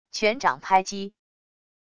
拳掌拍击wav音频